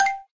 gameClick.mp3